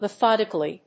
発音記号
• / ‐kəli(米国英語)
• / mʌˈθɑ:dɪkʌli:(英国英語)